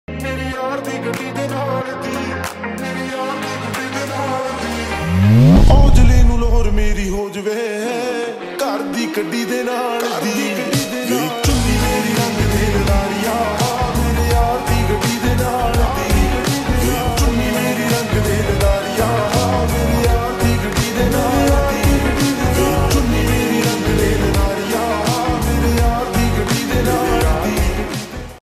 Dj Remix Ringtone